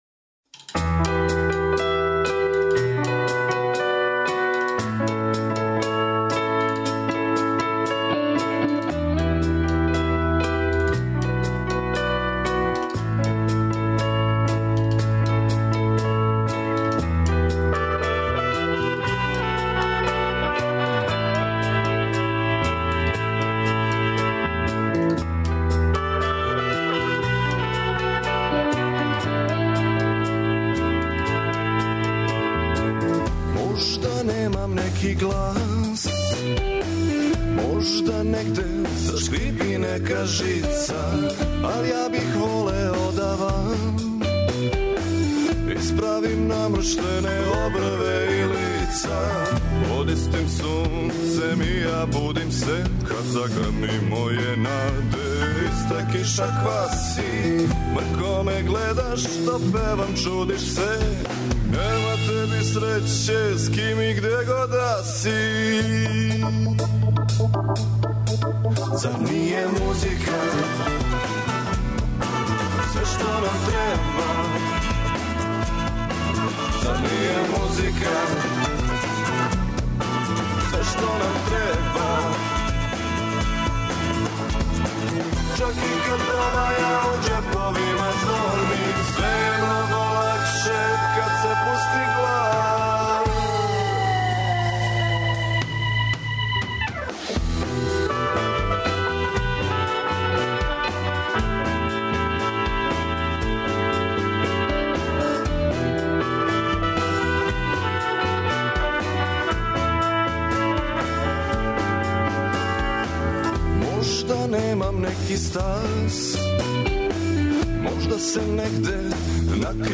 Свако вече, од поноћи на Двестадвојци у емисији Кажи драгичка гост изненађења! Музички гост се, у сат времена програма, представља слушаоцима својим ауторским музичким стваралаштвом, као и музичким нумерама других аутора и извођача које су по њему значајне и које вам препоручују да чујете.